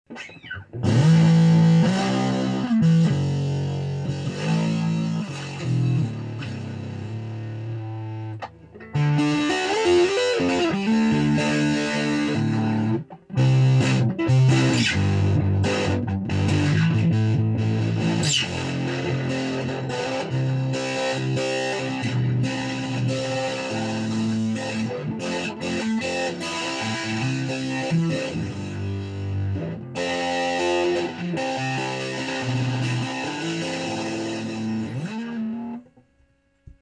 This amp is known for its thick and dirty sound.
• Raw signal recorded into a laptop with one of those headsets with a microphone on it. No compression, reverb, pedals, or talent whatsoever. What you hear is what you get!
• 1992 Strat deluxe plus, neck pickup and volume on 10
• Start of second verse sounds like I turned on a flange pedal. I did not; the amp just does that if I play a certain way (which I think is righteous).
Cut 2: Natural Flange
3-natural_flange.mp3